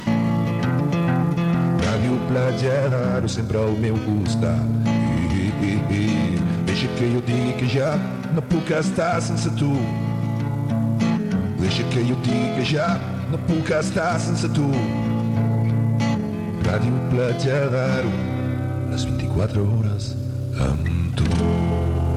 Cançó de l'emissora